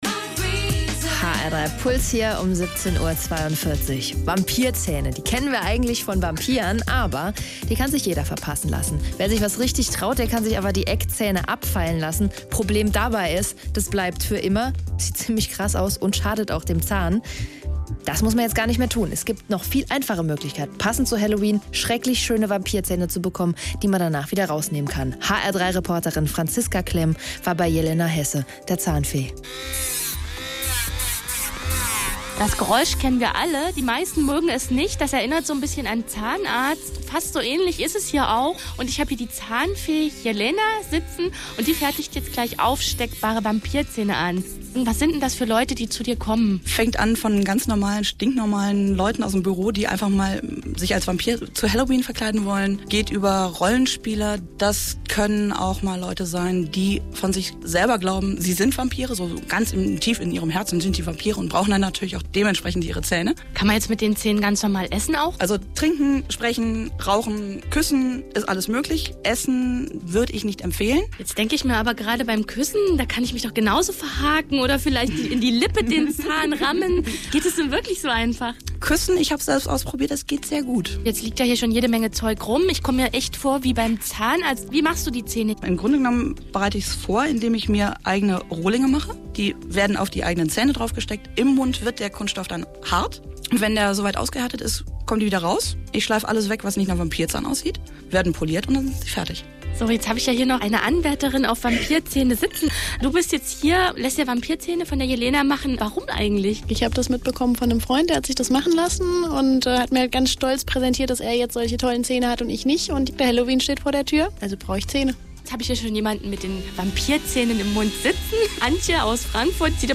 Reportage-Zahnfee.mp3